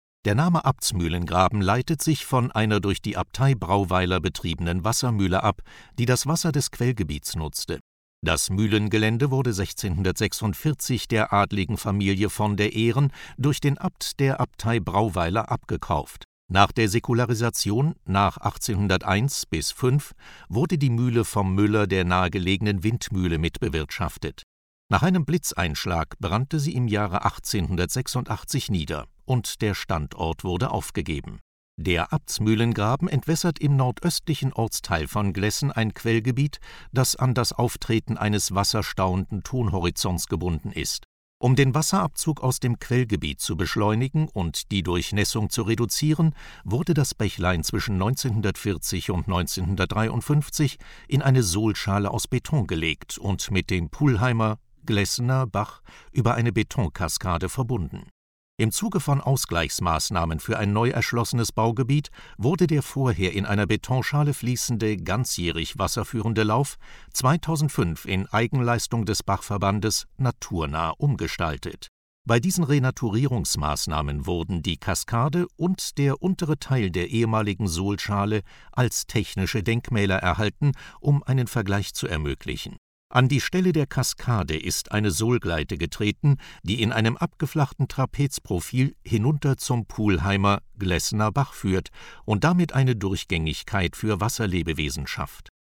: rausche
Renaturierungsmaßnahme Sohlgleite (Rausche) in etwas abgeflachtem Trapezprofil